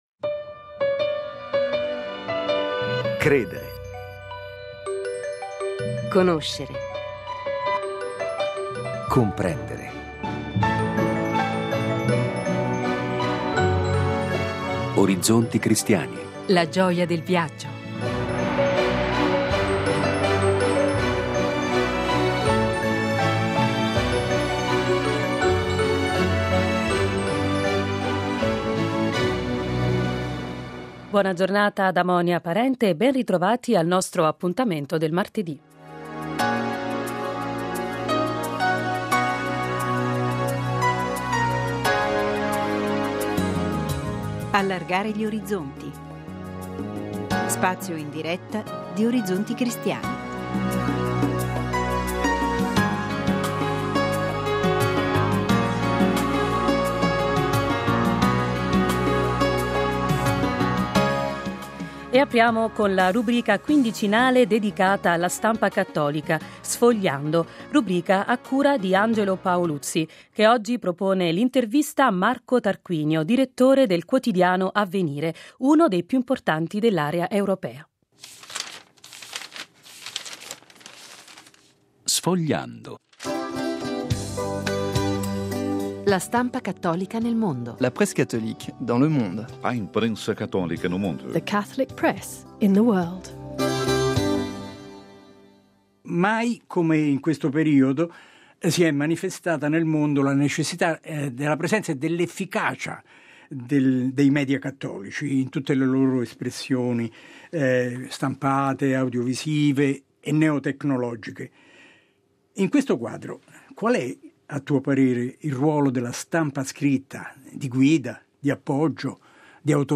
martedì 18 maggio Apre lo spazio in diretta del martedì la rubrica quindicinale dedicata alla stampa cattolica nel mondo: Sfogliando.